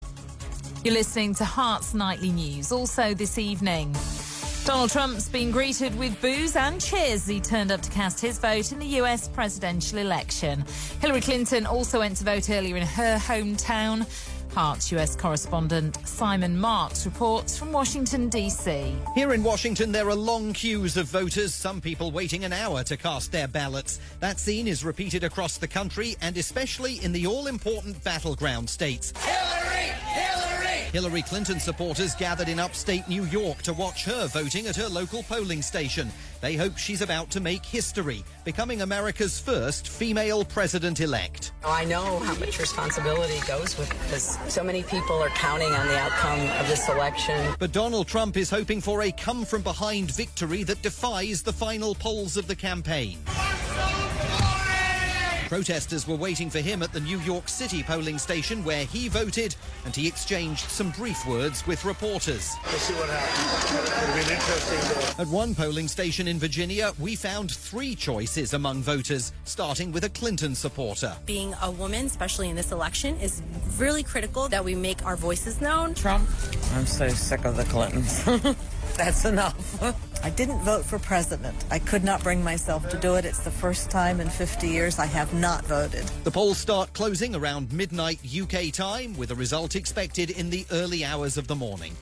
report for the Heart network of radio stations in the UK on the first few hours of Election Day in the US.